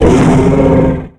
Cri de Polagriffe dans Pokémon X et Y.